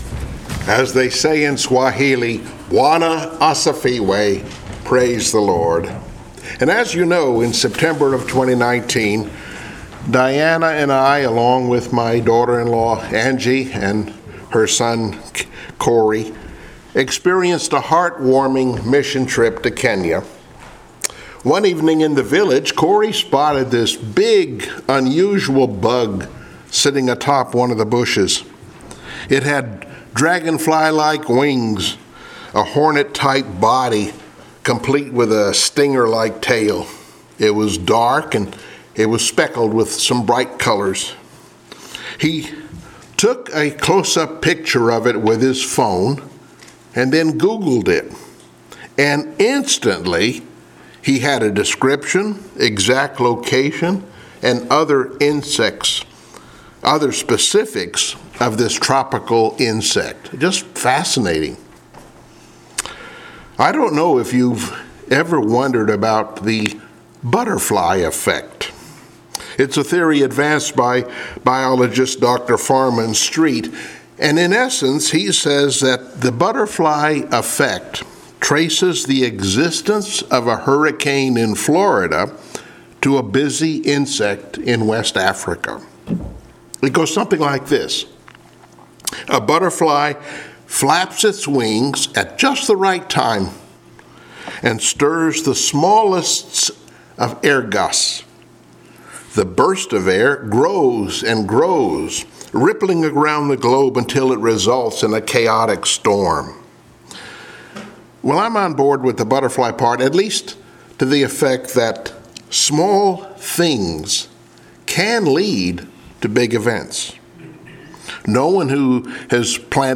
Passage: Esther 5 & 6 Service Type: Sunday Morning Worship